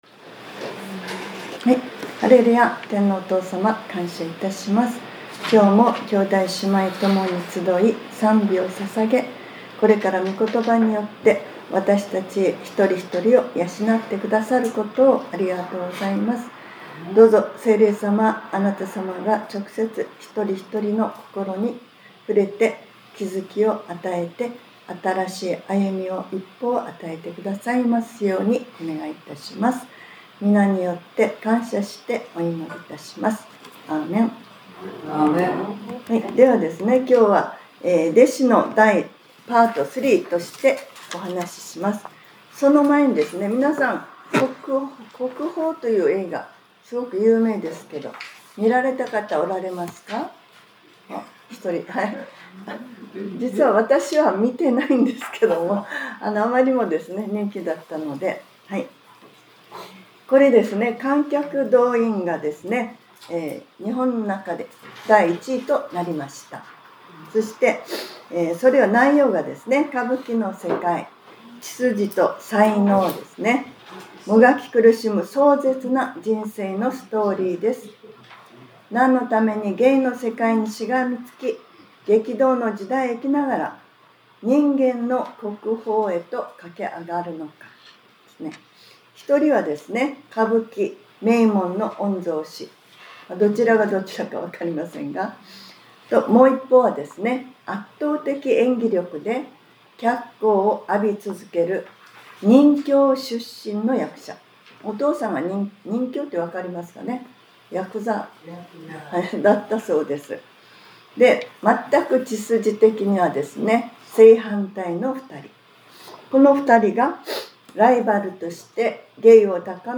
2026年01月18日（日）礼拝説教『 弟子ｰ３：祝福 』 | クライストチャーチ久留米教会